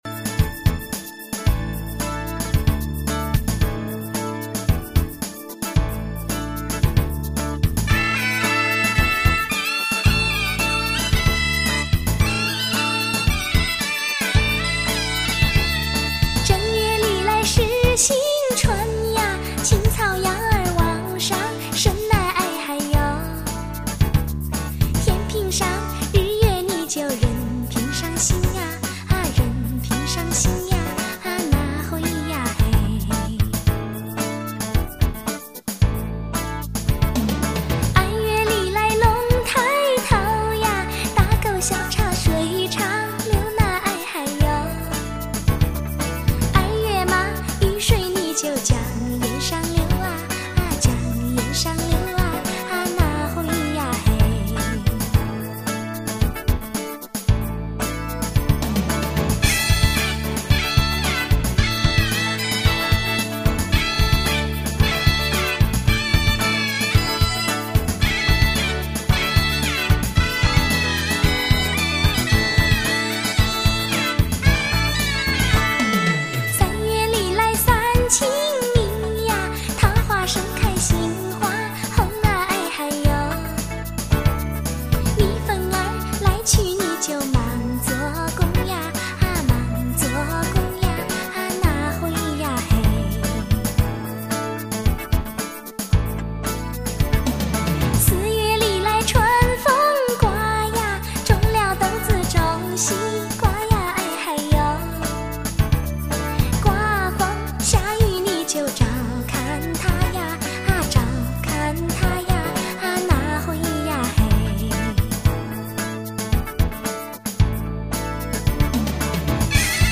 本专辑收录了全国各地的经典民歌 大多由通俗歌星重新演绎 是不可多得的民歌版本